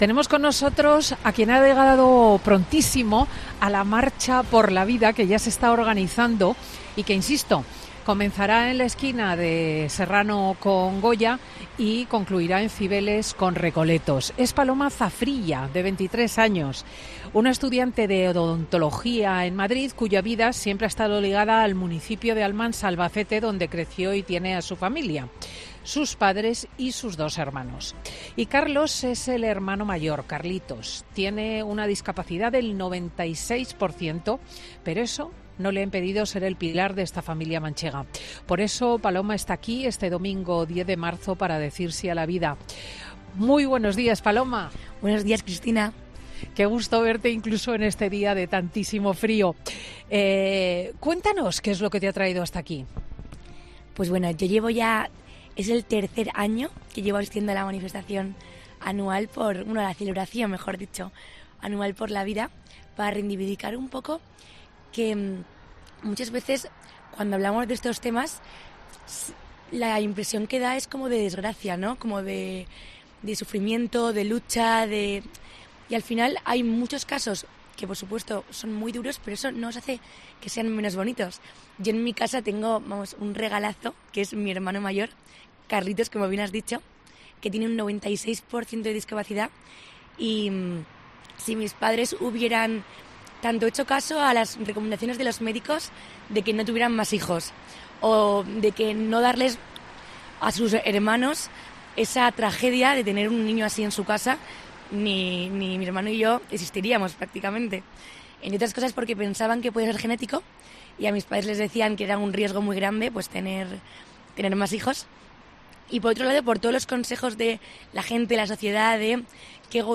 Cristina López Schlichting, directora de 'Fin de Semana', te cuenta desde las calles que hoy se celebra la gran fiesta anual en defensa de la vida humana.